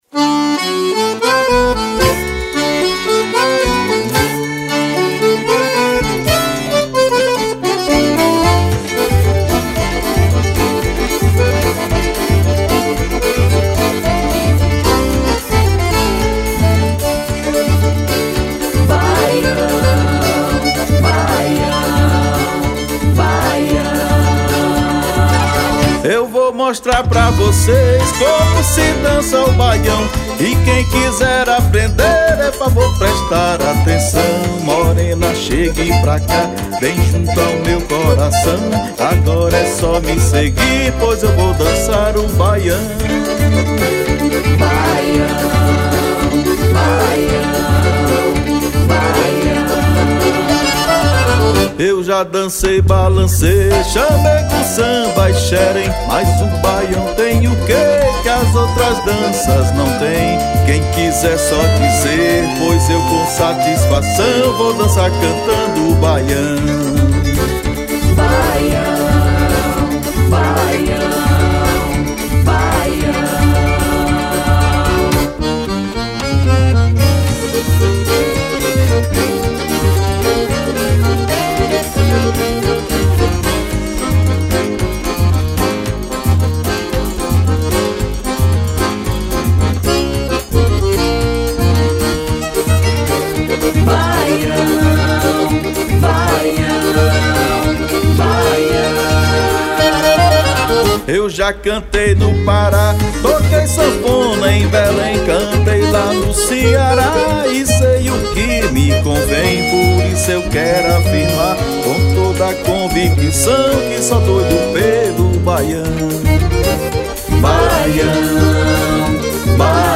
• Acordeom
• Violino
• Zabumba
• Triângulo e percussão
• Baixo e cavaquinho
• Backing Vocal